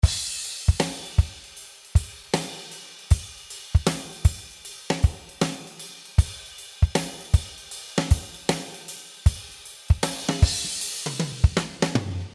With 15 loops playing tom fills.
This cool Triplet beat is in 78 bpm.
The track reminds the beat from the 90's hit (close to you) by maxi priest.